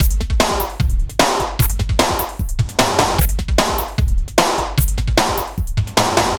CRATE HC DRM 1.wav